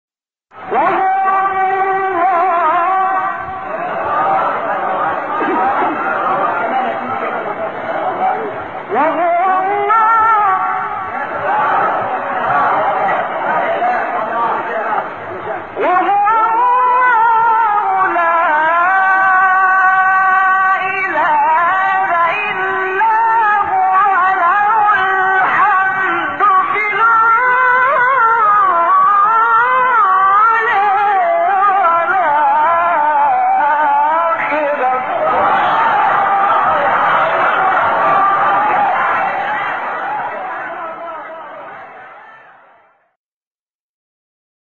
استاد «محمد عبدالعزیز حصان» یکی از مشاهیر تلاوت قرآن کریم است و به لحاظ برجستگی در ادای نغمات و دقت در ظرافت‌های وقف و ابتداء او را «ملک الوقف و الإبتدا و التنغیم» یعنی استاد الوقف و الابتداء و تلوین النغمی لقب داده‌اند.
در ادامه ۵ قطعه کوتاه از زیباترین تلاوت‌های استاد محمد عبدالعزیز حصان ارائه می‌شود.